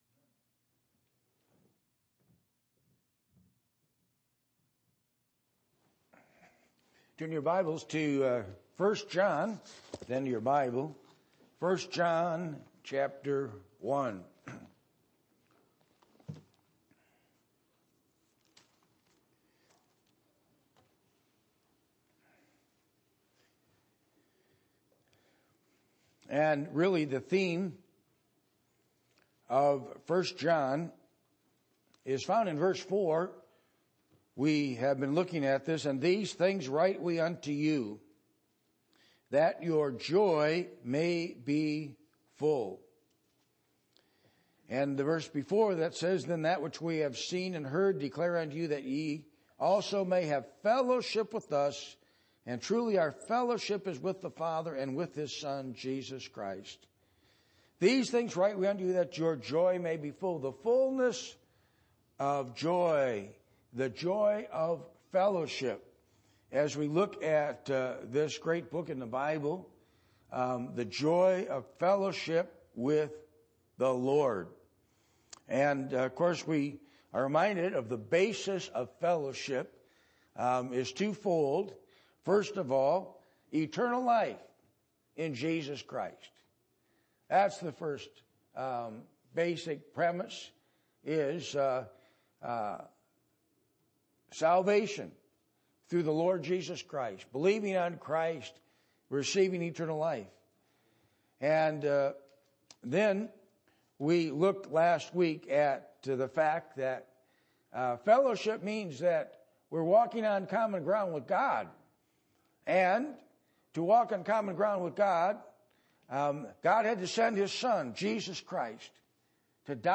1 John 1:1-10 Service Type: Sunday Morning %todo_render% « Asking